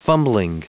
Prononciation du mot fumbling en anglais (fichier audio)
Prononciation du mot : fumbling